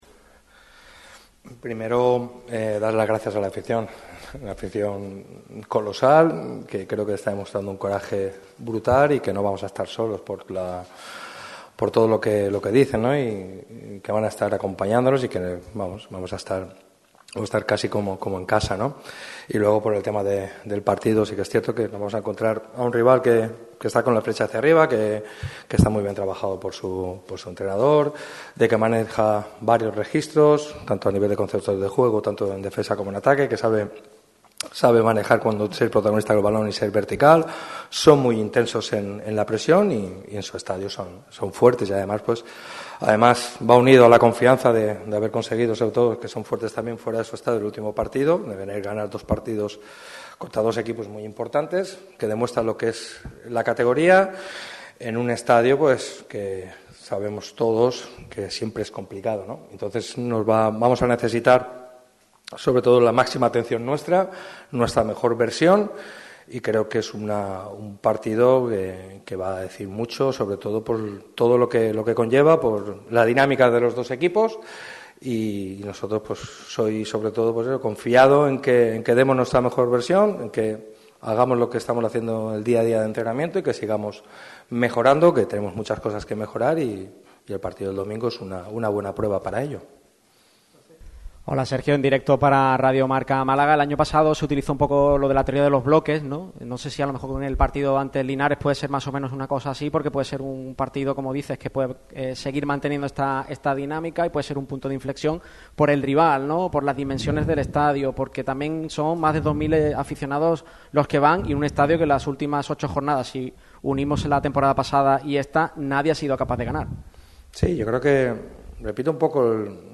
Viernes de rueda de prensa en Martiricos.